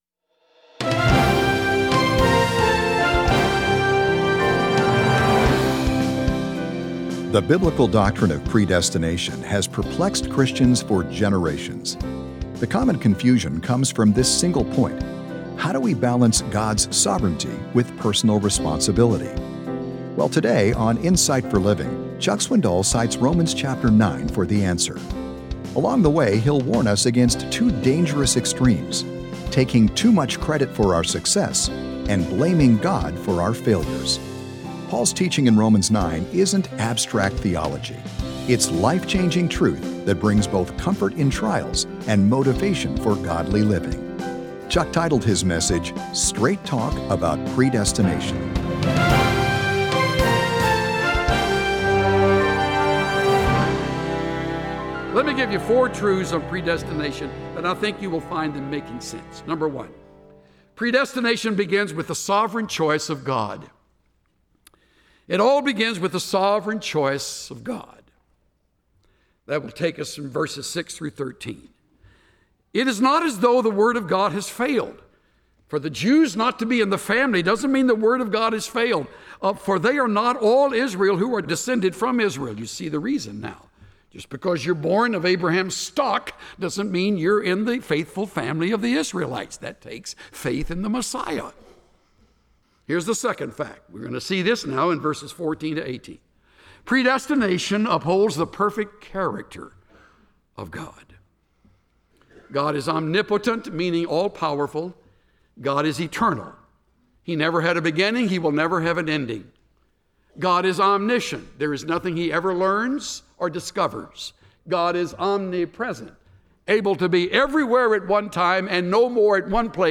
Join the millions who listen to the lively messages of Chuck Swindoll, a down-to-earth pastor who communicates God’s truth in understandable and practical terms—with a good dose of humor thrown in. Chuck’s messages help you apply the Bible to your own life.